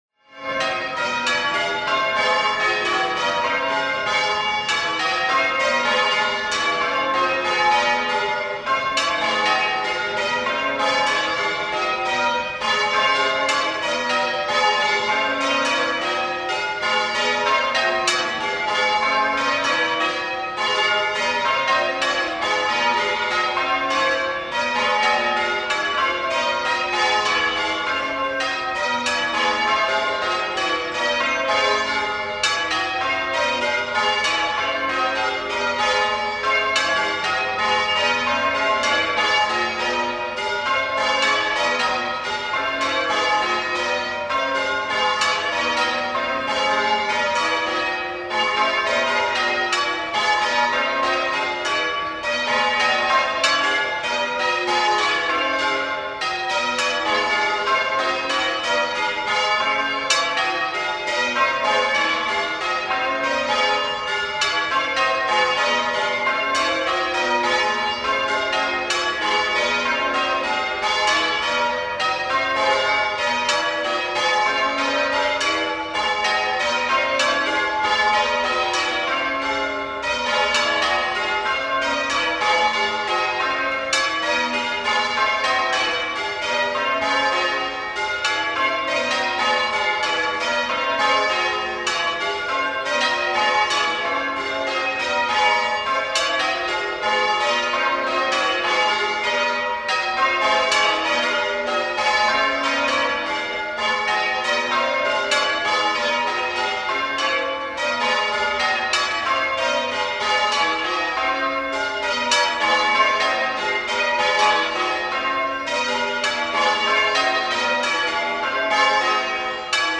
a longer recording on the tower's page on this very website.
WoolpitPBM.mp3